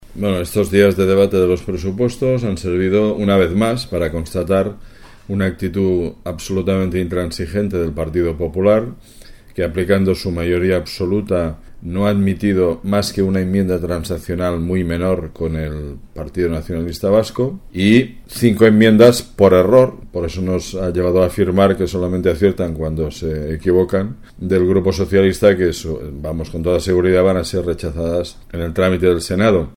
Declaraciones de Joan Rangel al terminar la votación de las enmiendas a los PGE2015 en las que el PP sólo ha aceptado 5 enmiendas socialistas y tras votar por error 13/11/2014